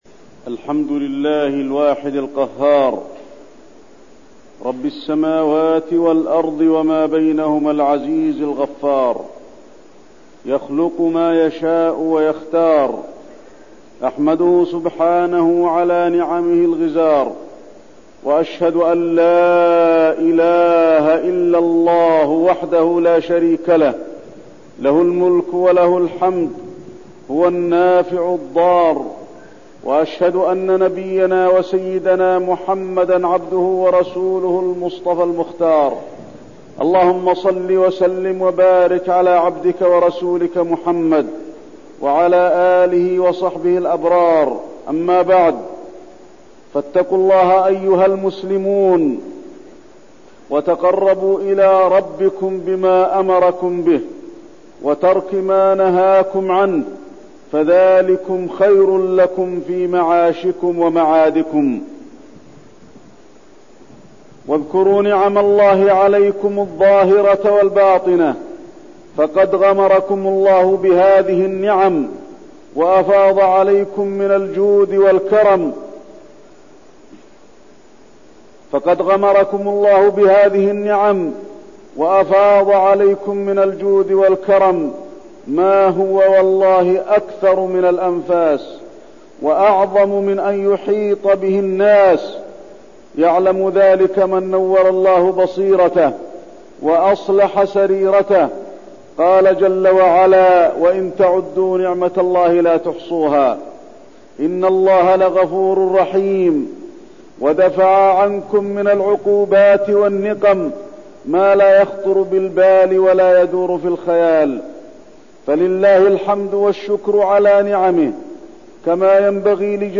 تاريخ النشر ٧ ذو الحجة ١٤١٠ هـ المكان: المسجد النبوي الشيخ: فضيلة الشيخ د. علي بن عبدالرحمن الحذيفي فضيلة الشيخ د. علي بن عبدالرحمن الحذيفي نعمة الإسلام